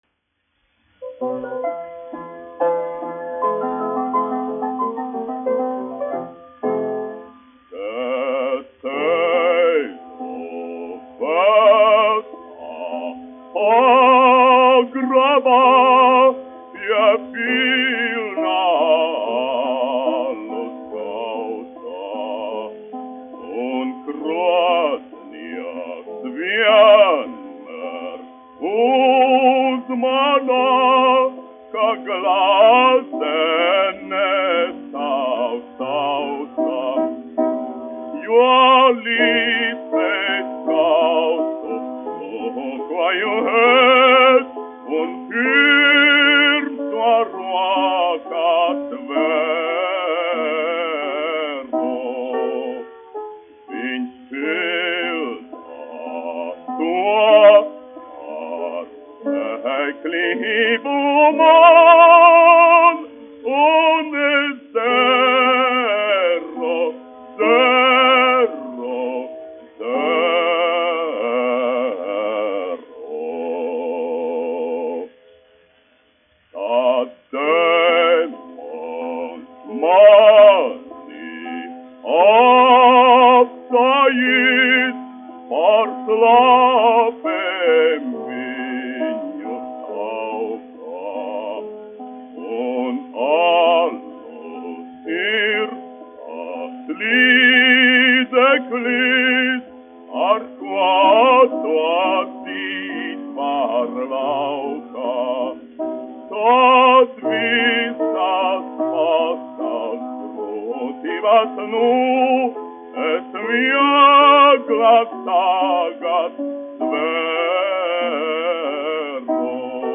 1 skpl. : analogs, 78 apgr/min, mono ; 25 cm
Dziesmas (zema balss) ar klavierēm
Skaņuplate
Latvijas vēsturiskie šellaka skaņuplašu ieraksti (Kolekcija)